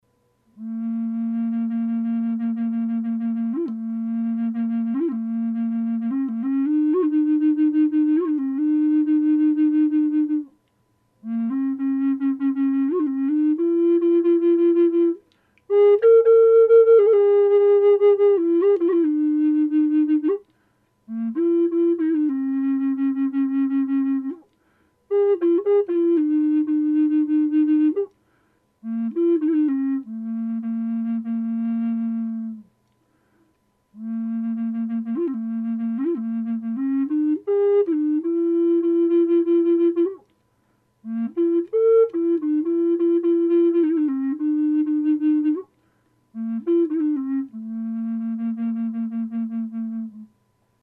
~ Big-Tree Bass Flutes ~
A Sound Sample of the Big Tree Bass A#
Without reverb